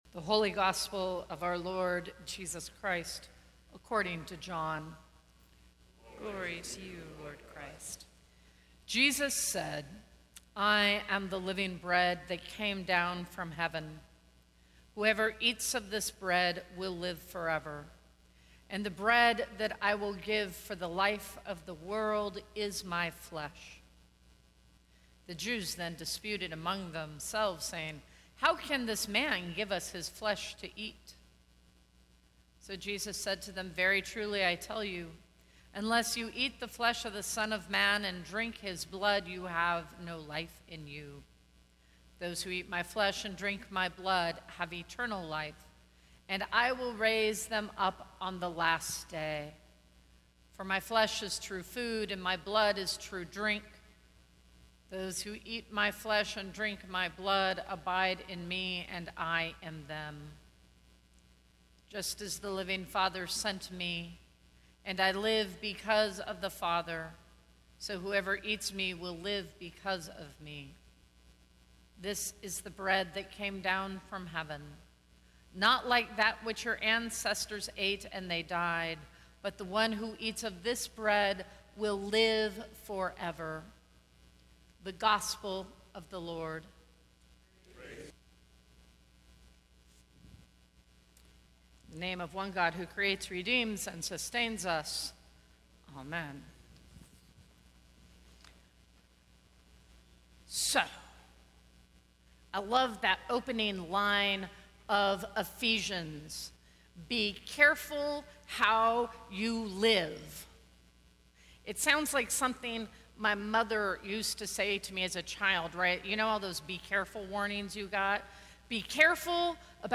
Sermons from St. Cross Episcopal Church Be Careful Aug 19 2018 | 00:15:19 1x 00:00 / 00:15:19 Subscribe Share Apple Podcasts Spotify Overcast RSS Feed Share Link Embed